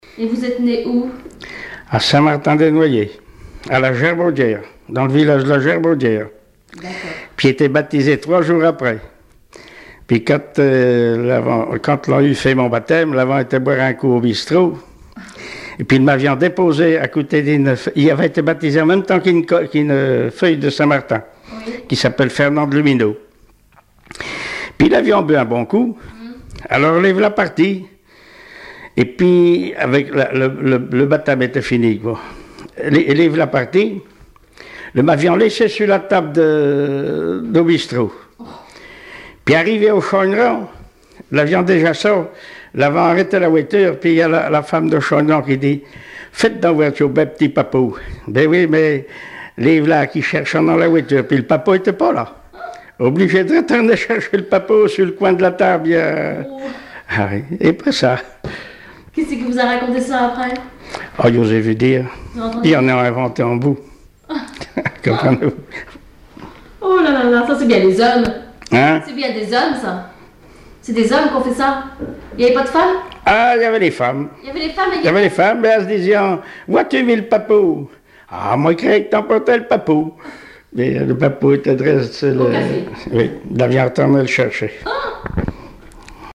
Témoignages et chansons traditionnelles et populaires
Catégorie Témoignage